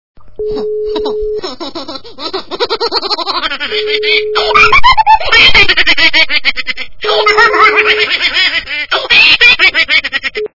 » Звуки » Смешные » Смех обкуренного - Можешь так
При прослушивании Смех обкуренного - Можешь так качество понижено и присутствуют гудки.
Звук Смех обкуренного - Можешь так